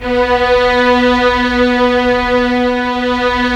Index of /90_sSampleCDs/Roland LCDP13 String Sections/STR_Violins I/STR_Vls1 Sym slo